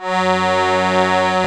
F#2 ACCORD-L.wav